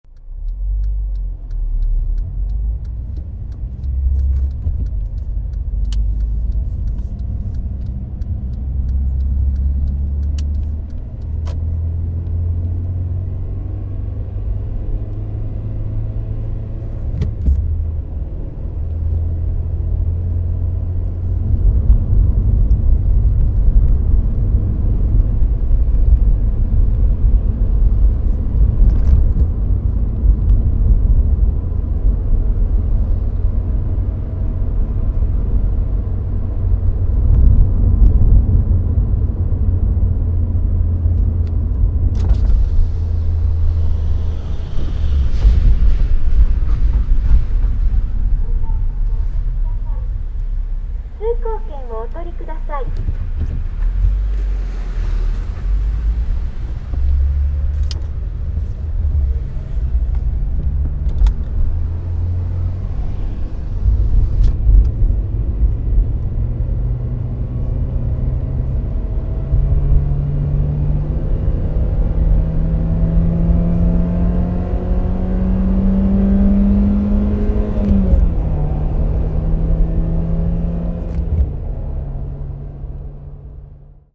上了高速路
描述：上高速的声音。你可以听到机器人在门口用女声喂票。我开着我的本田车。AE5100和MD录音机。
Tag: 发动机 现场录音 公路 运输 公路